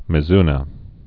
(mĭ-znə)